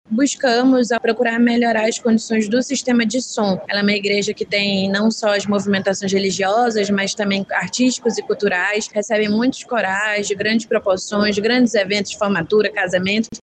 Ainda segundo a superintendente, foi feita uma recuperação mais detalhada no sistema de som da Catedral.